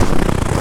STEPS Snow, Walk 21.wav